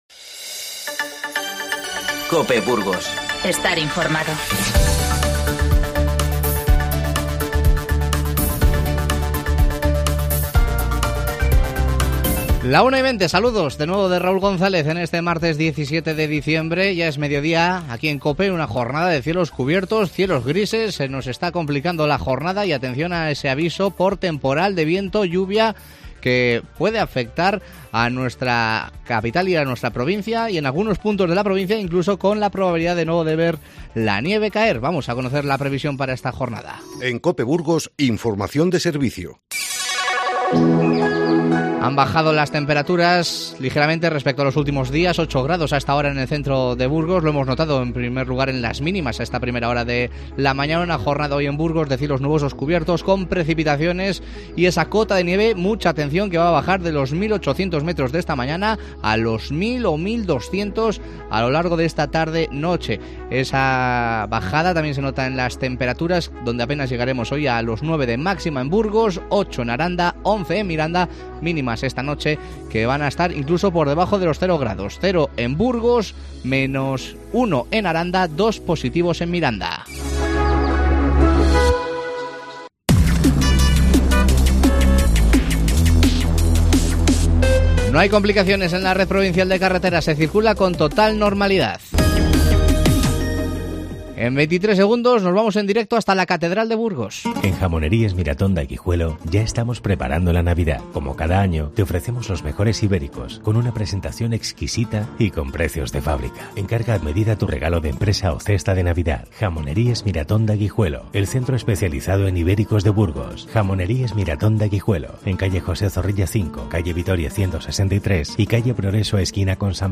AUDIO: Desde la Catedral de Burgos, te avanzamos los detalles de la programación para 2020 por el VIII Centenario de la Seo en 2021.